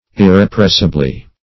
Search Result for " irrepressibly" : The Collaborative International Dictionary of English v.0.48: Irrepressibly \Ir`re*press"i*bly\, adv.
irrepressibly.mp3